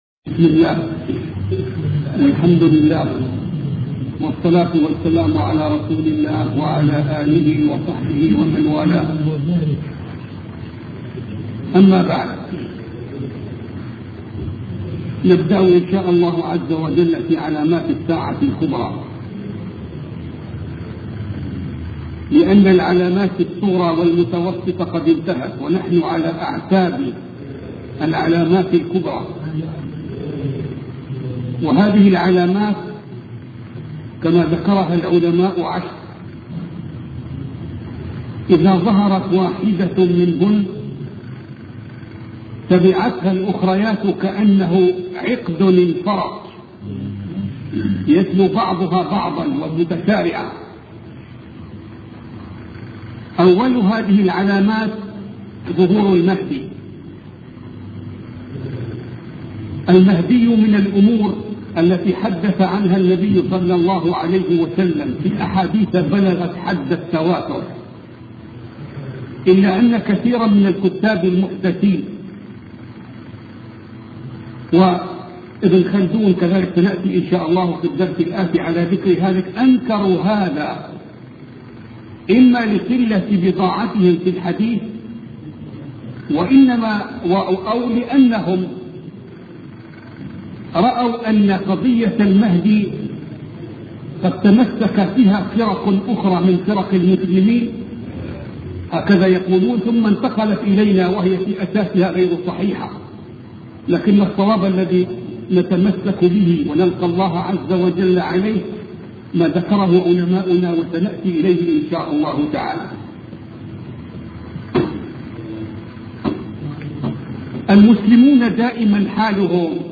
سلسلة محاطرات